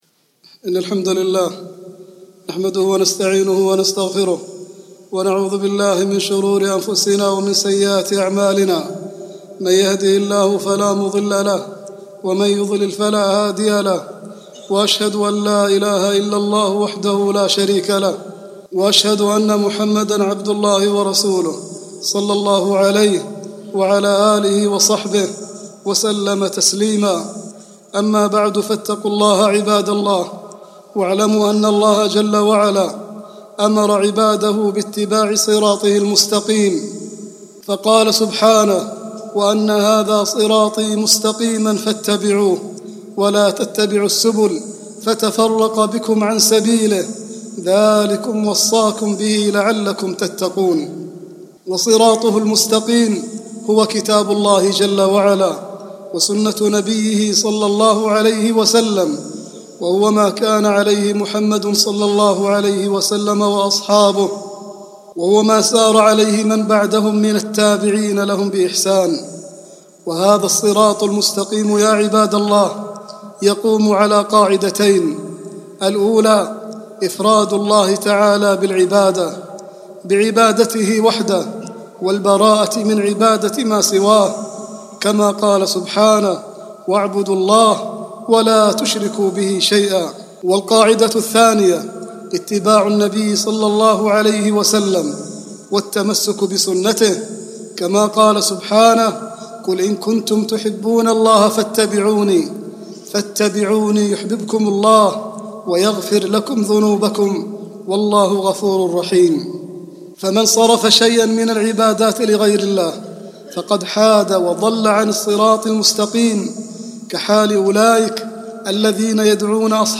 khutbah-6-5-38.mp3